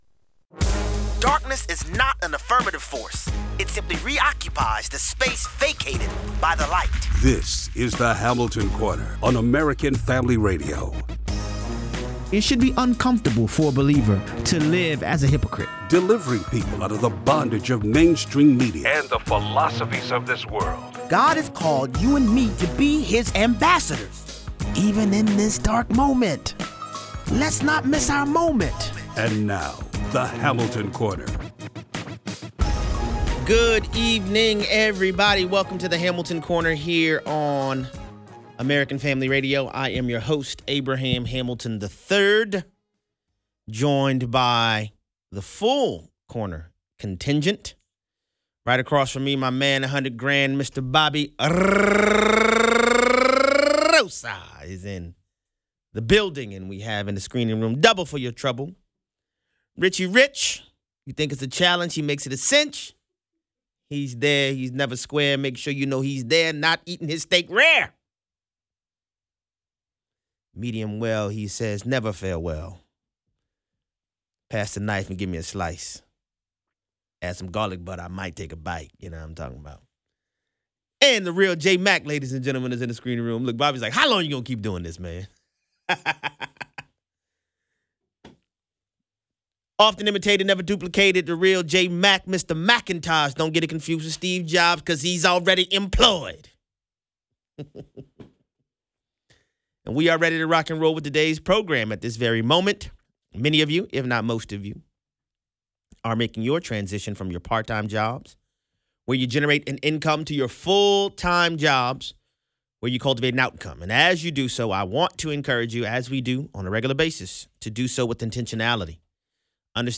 Reasons for delayed and avoided marriage: data vs. real life. Callers weigh in.